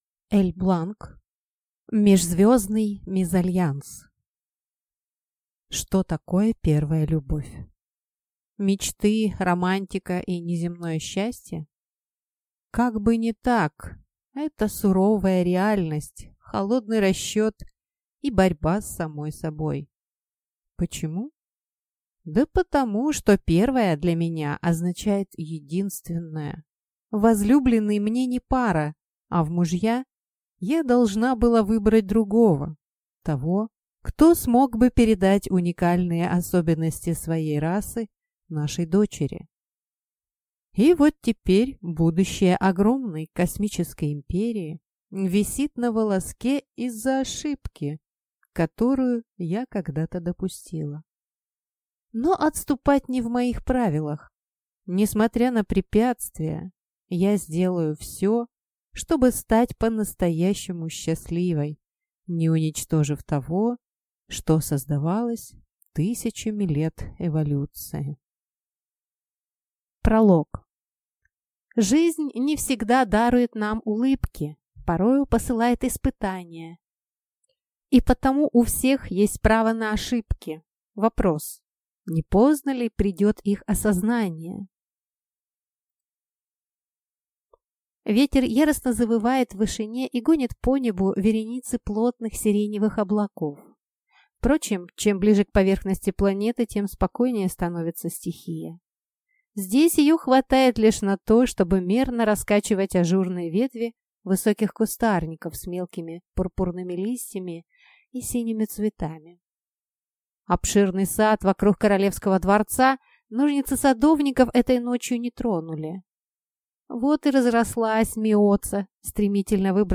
Аудиокнига Межзвездный мезальянс | Библиотека аудиокниг